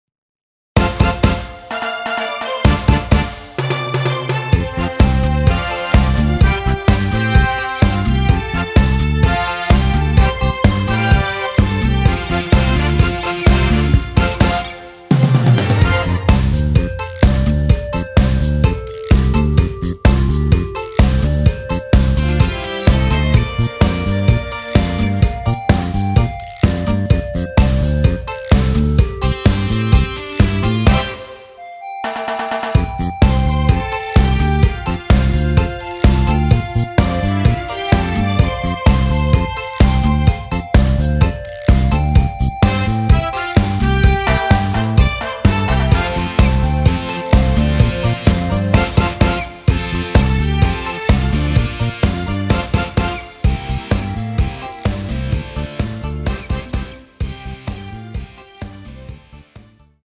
원키에서(+3)올린 멜로디 포함된 MR 입니다.
앞부분30초, 뒷부분30초씩 편집해서 올려 드리고 있습니다.
중간에 음이 끈어지고 다시 나오는 이유는